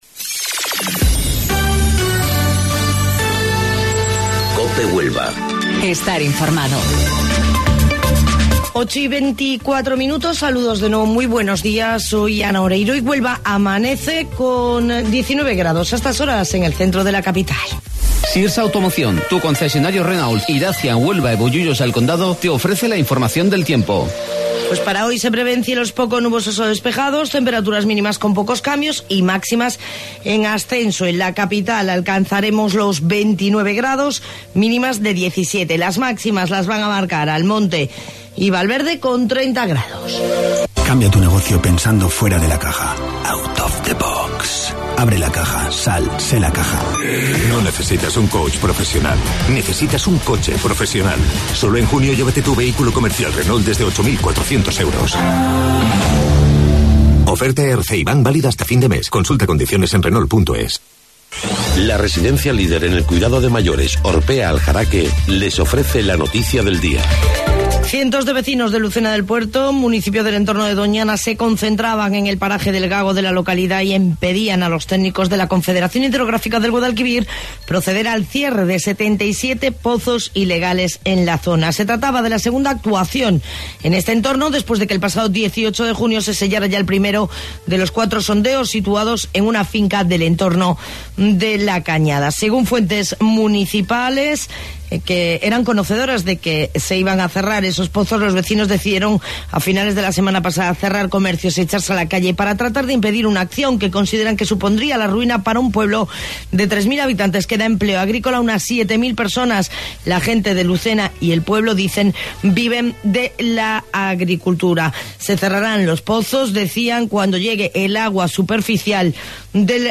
Herrera en Cope Huelva - Informativo 08:25 - 25 Junio